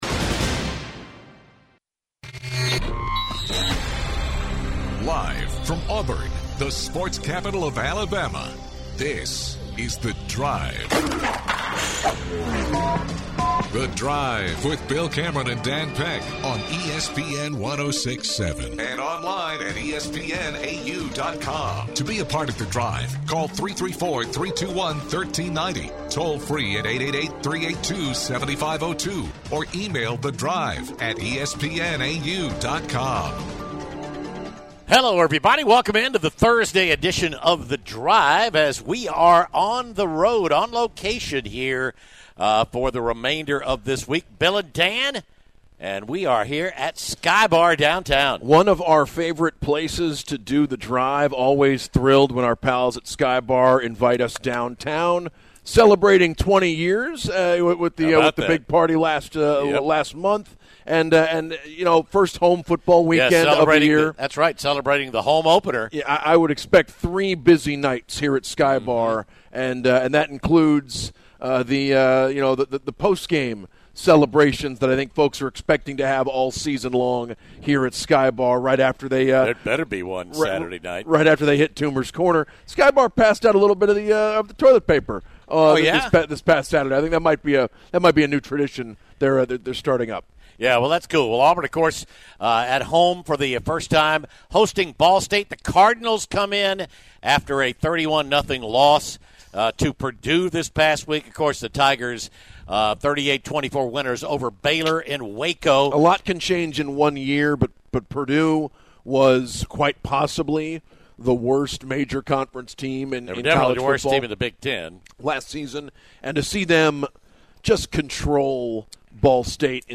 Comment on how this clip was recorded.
The guys are live from Sky Bar.